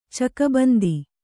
♪ cakabandi